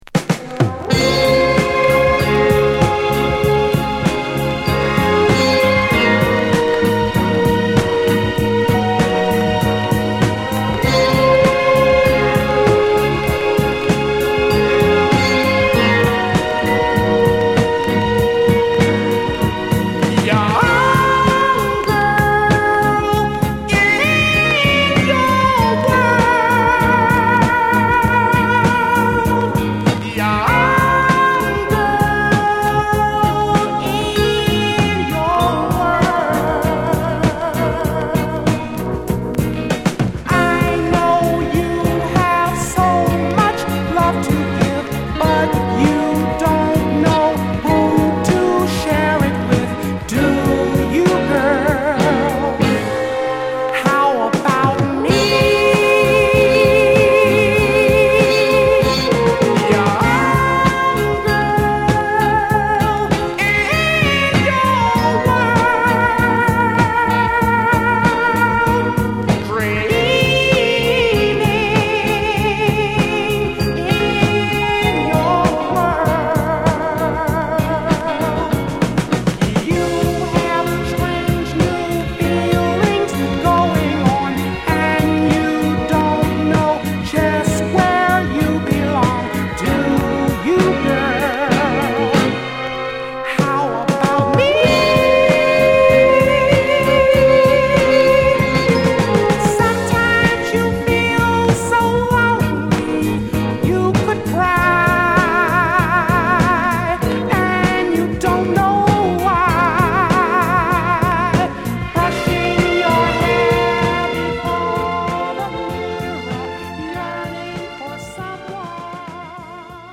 グルーヴィーなベースにシッカリ打ったドラムがボトムを支えるミディアムダンサーなトラックで歌いあげるナイスソウル！
＊チリノイズ有り。